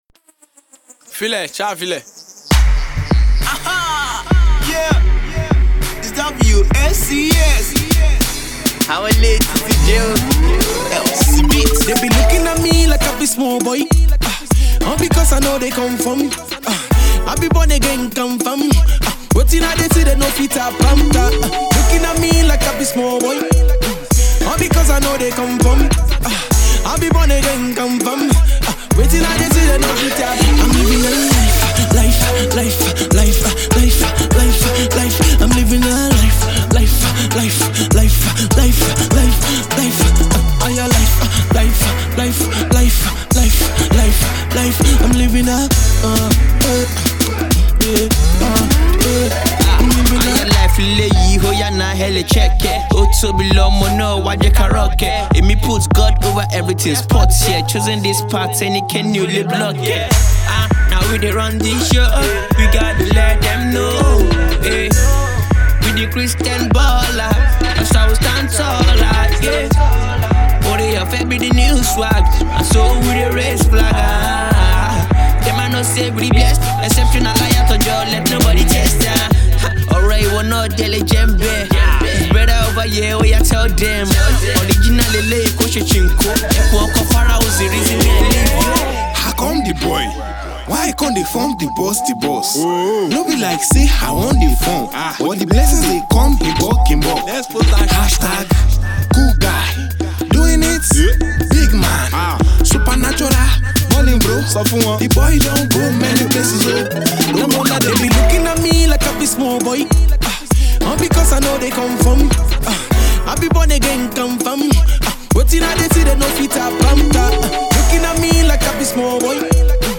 Christian rap group